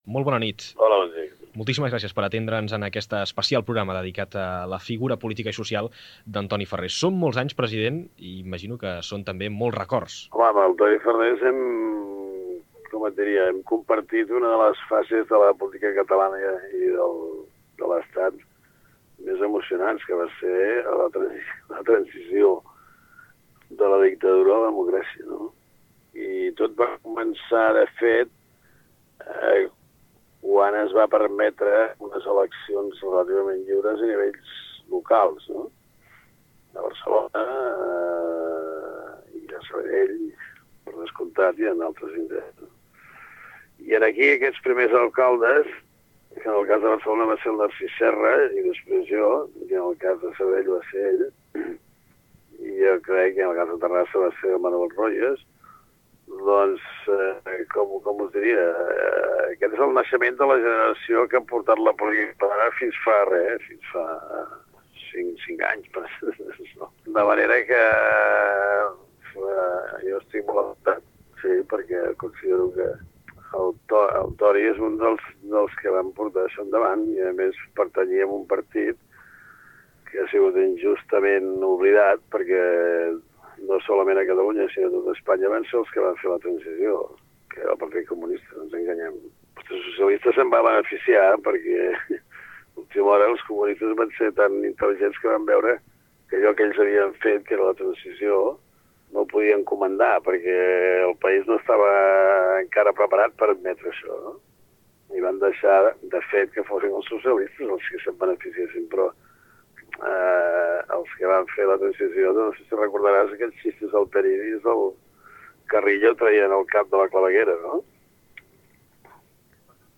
Fragment d'una entrevista a Pasqual Maragall, després de la mort de l'ex alcalde de Sabadell, Antoni Farrés. Al final veu d'Antoni Farrés.
Informatiu